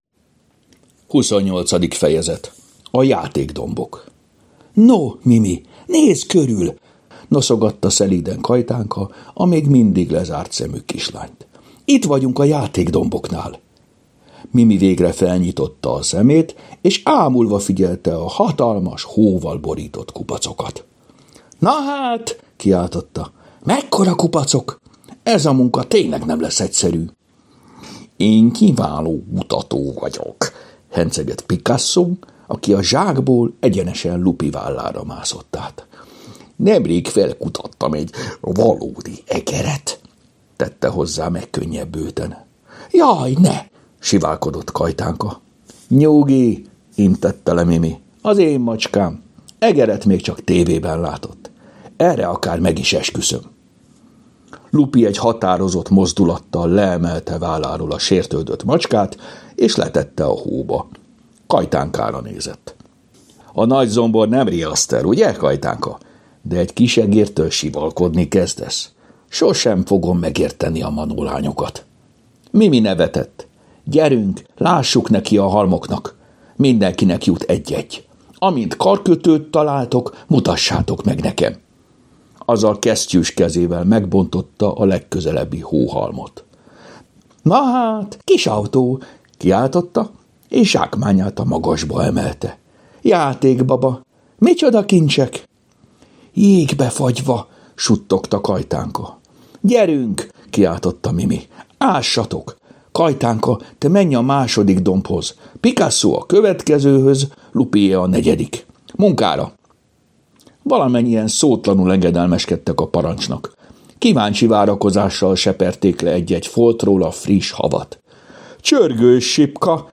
Hangos mese: A játékdombok Mindet meghallgatom ebből a folyamból!